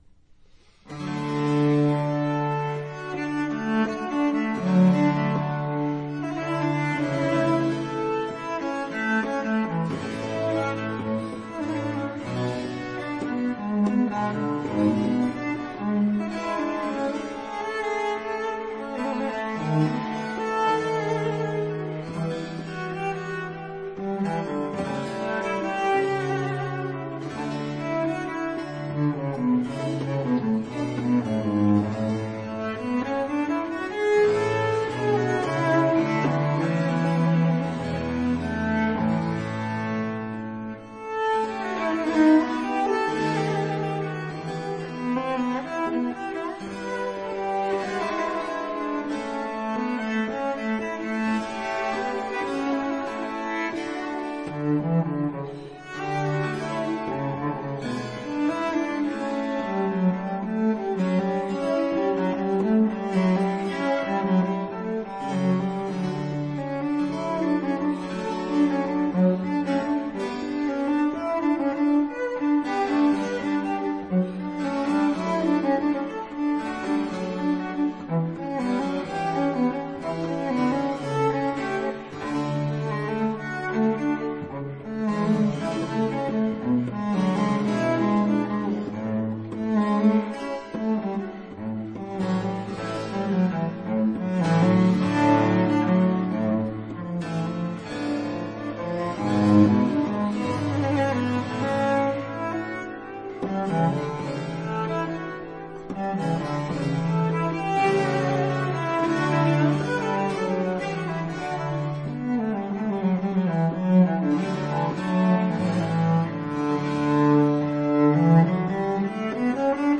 都偏向簡單的架構與簡單的旋律，與簡單的情感表達。
而表達的情感甚多是低沈與悲傷。
另外，這些錄音使用了十把大提琴，
只有一把是古琴，其他九把都是複製。